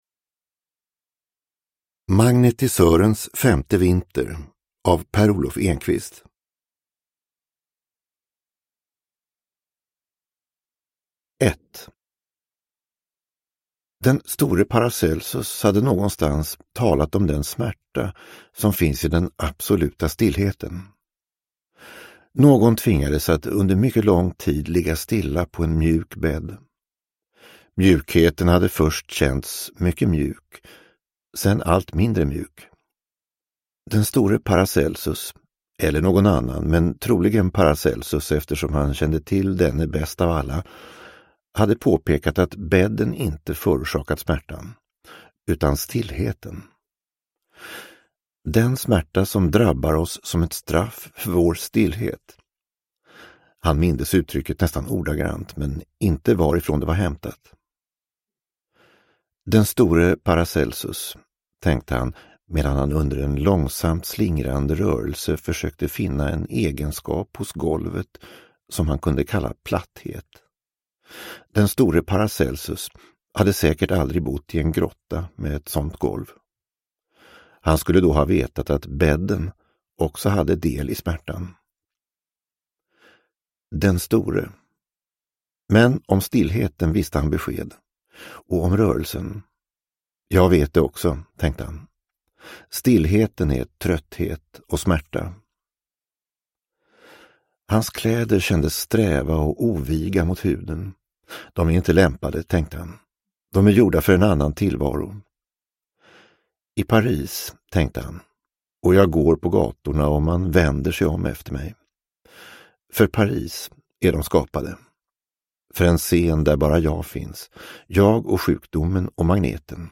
Magnetisörens femte vinter – Ljudbok – Laddas ner